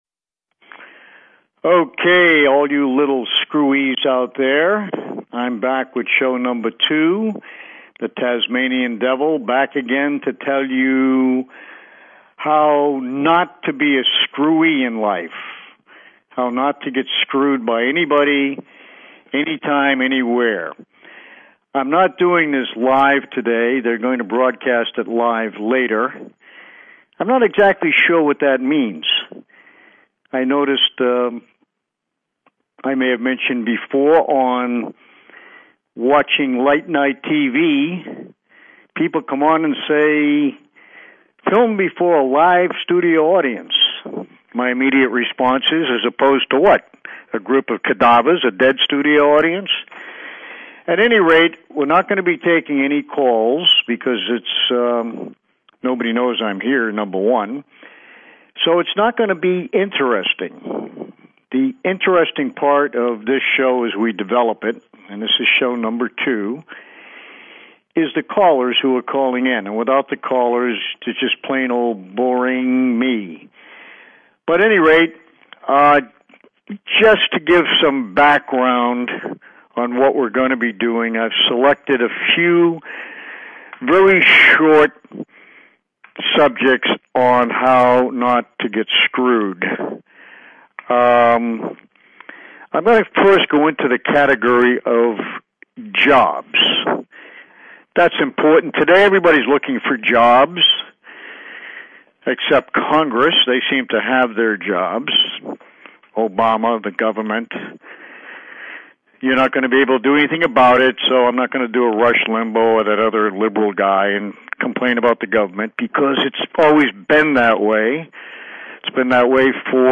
Talk Show Episode, Audio Podcast, How_To_NOT_Get_Screwed and Courtesy of BBS Radio on , show guests , about , categorized as
This is a show of talk, NO music…NO music in the background, the foreground, the ground next door, and at the front or back of the show – NO BLOODY MUSIC.